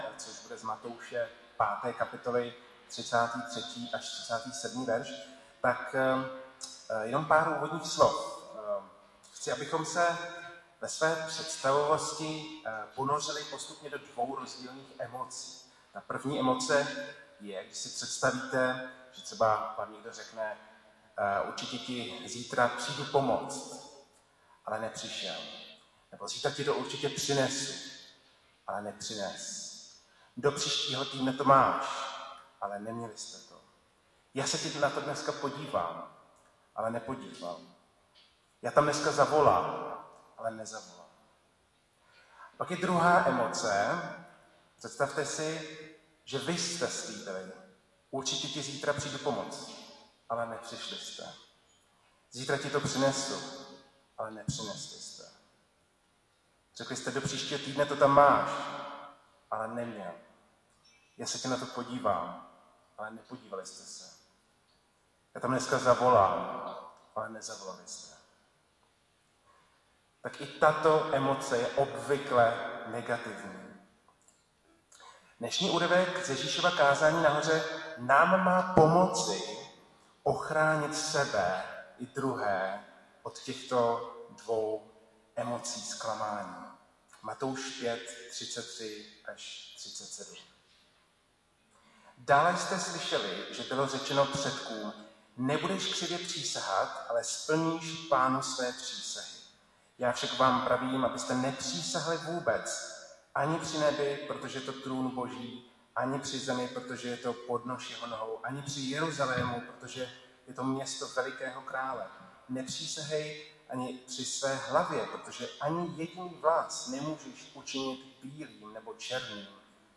Nedělní kázání 17.10.2021 – O přísaze